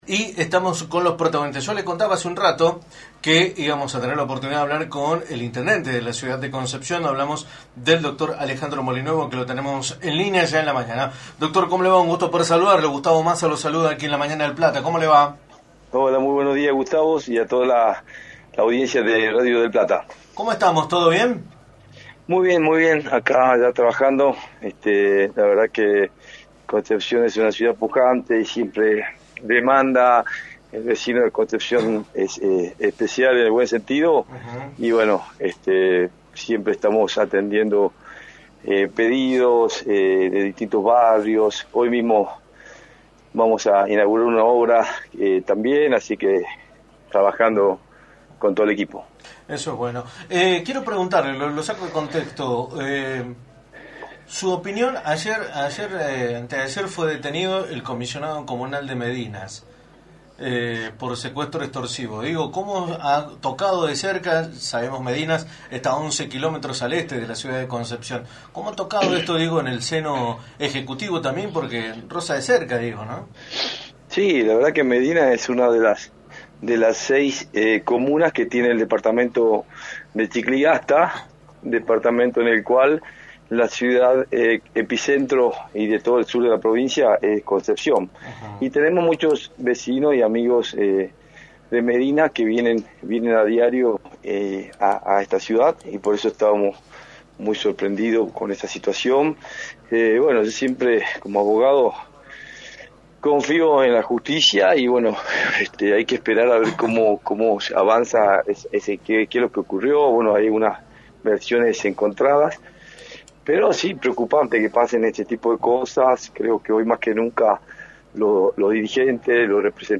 Alejandro Molinuevo, Intendente de Concepción, analizó en Radio del Plata Tucumán, por la 93.9, la situación política de la provincia, luego de la polémica por la coparticipación.